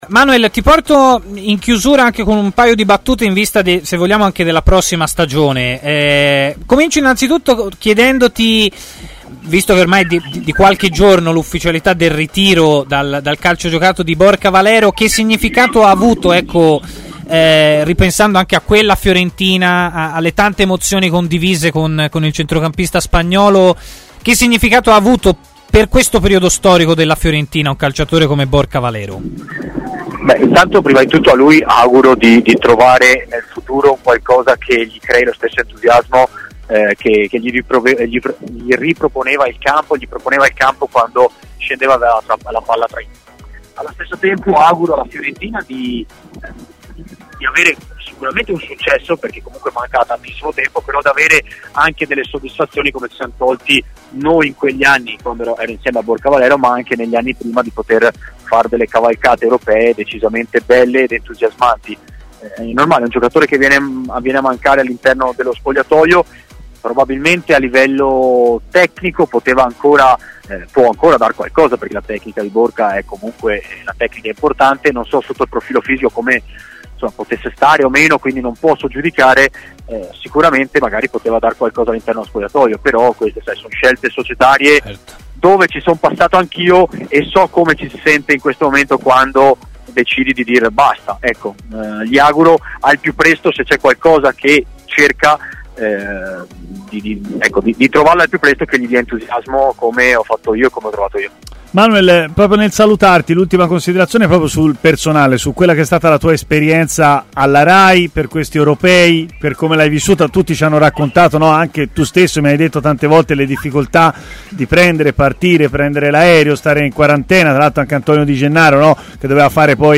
L'ex terzino Manuel Pasqual, oggi commentatore per Rai Sport, ha così parlato a Stadio Aperto di Damsgaard, giocatore accostato al Milan.